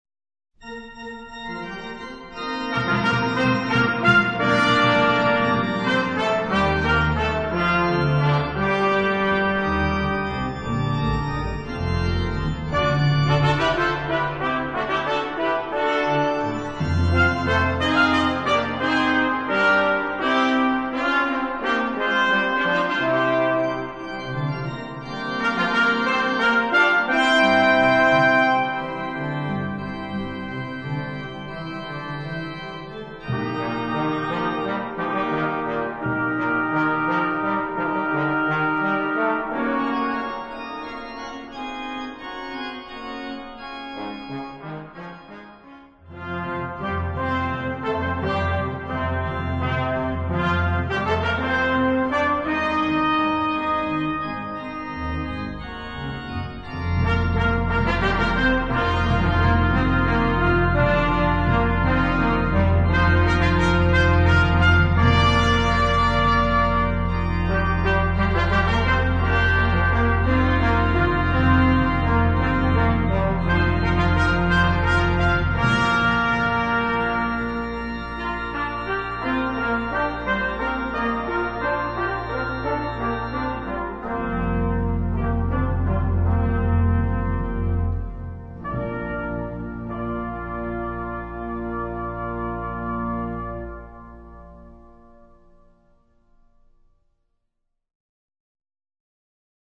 Brass Quartet, Timpani, and Organ
Voicing: 4 Brass